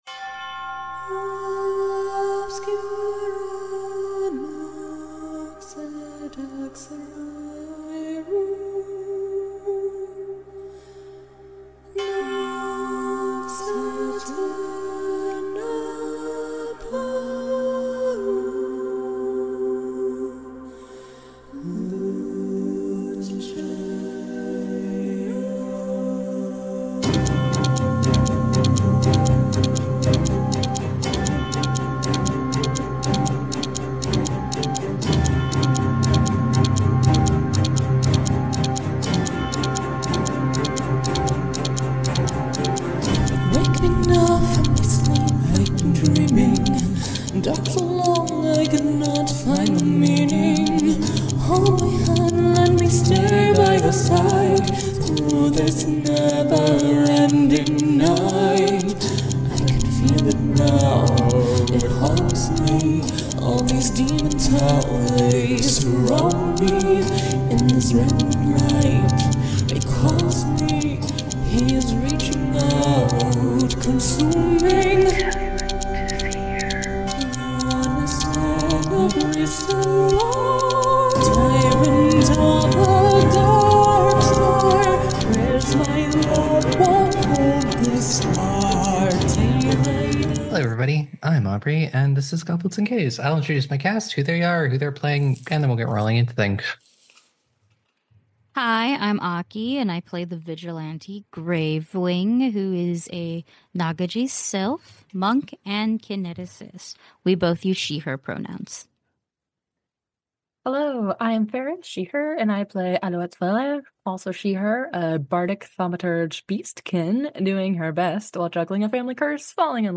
A gaggle of gays play Pathfinder 2e, our flagship campaign Blood of Kings releases every Wednesday 9AM EST!
… continue reading 287 episodes # Theater # Arts # Comedy # Improv # Audio Drama # [email protected] (Goblets and Gays # Goblets And Gays